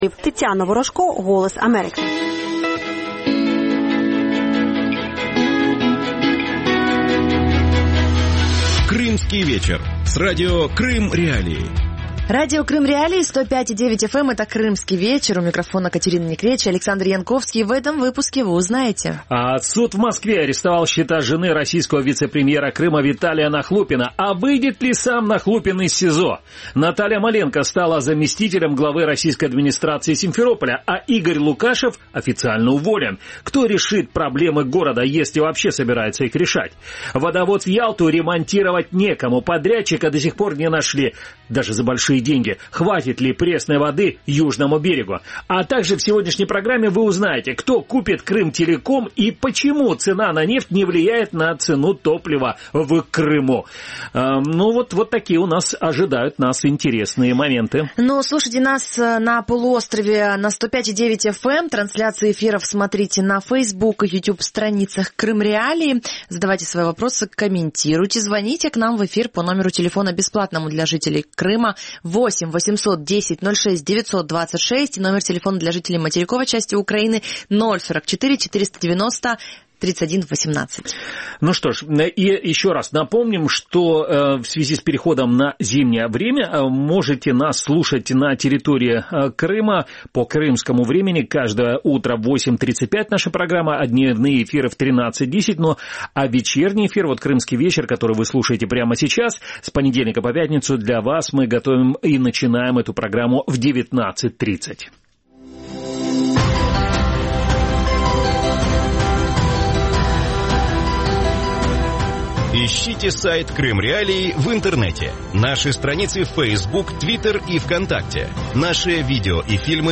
Хватит ли пресной воды Южному Берегу Крыма? Эти и другие актуальные темы в студии Радио Крым.Реалии в т